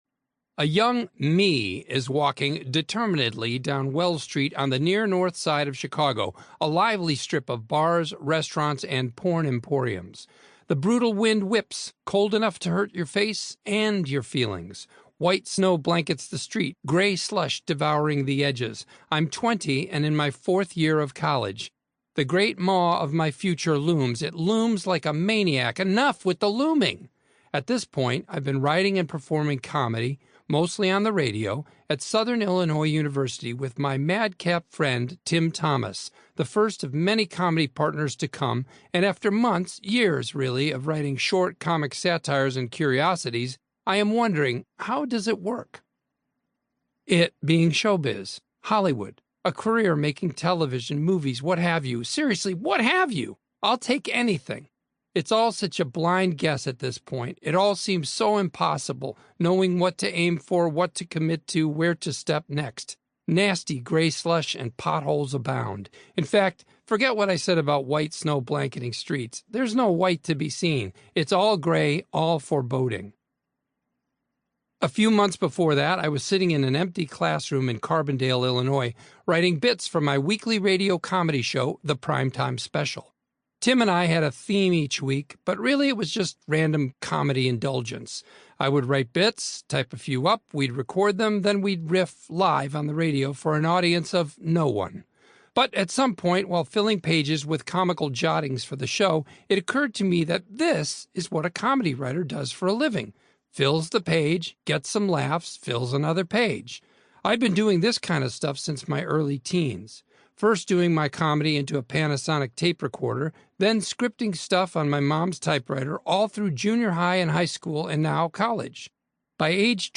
Comedy Comedy Comedy Drama Audiobook ⭐ Bob Odenkirk ⭐ Free Audiobooks in English 🎧 [VM5CrsVuA-o].mp3